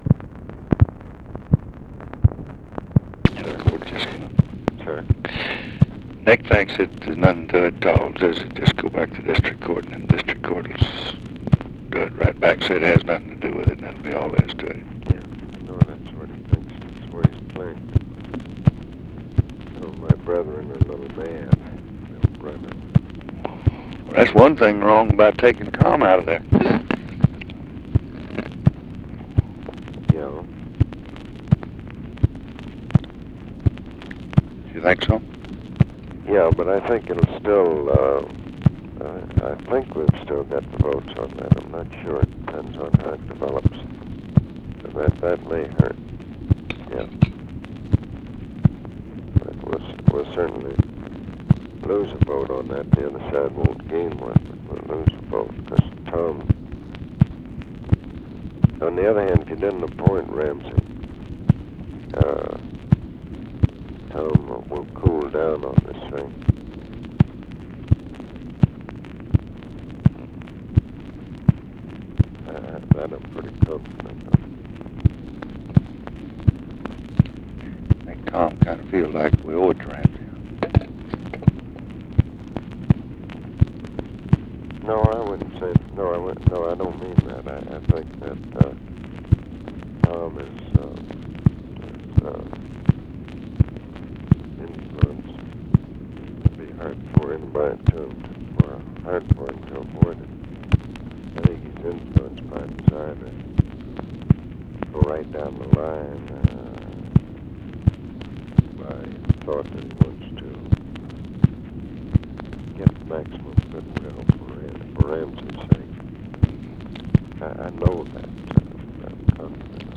Conversation with ABE FORTAS, September 22, 1966
Secret White House Tapes